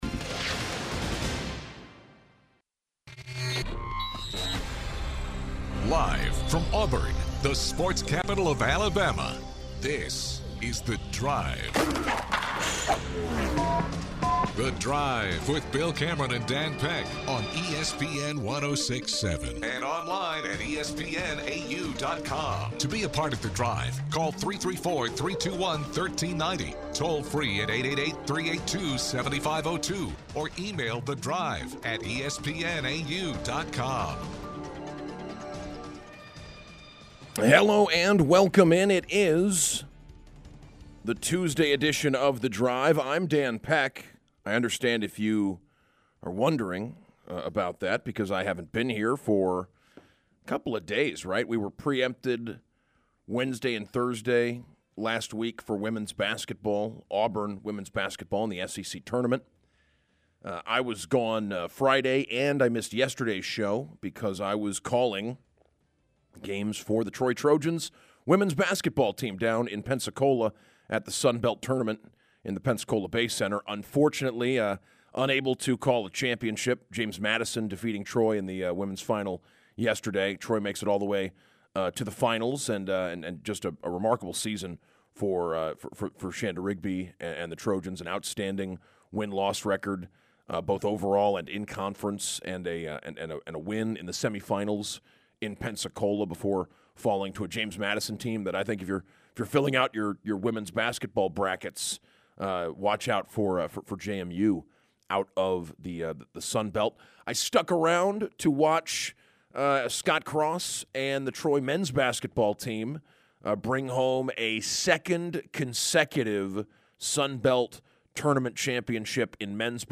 calling in from Nashville to preview the SEC Tournament